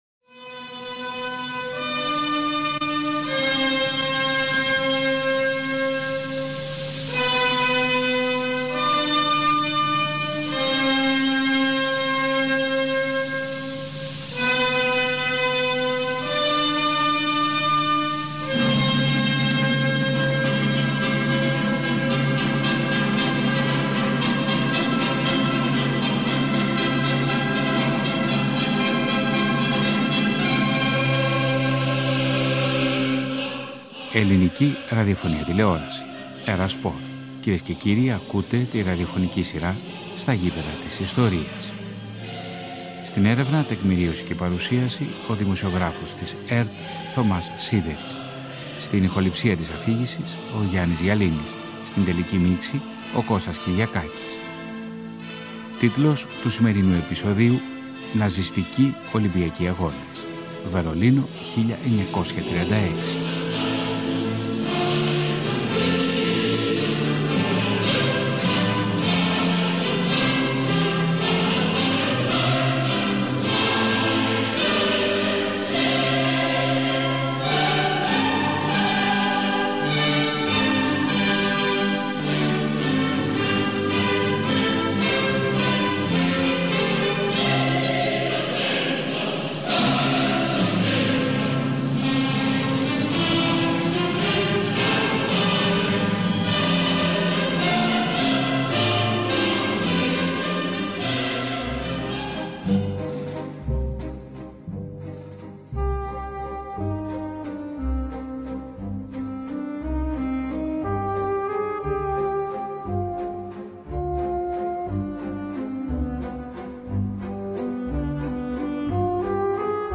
ραδιοφωνικό ντοκιμαντέρ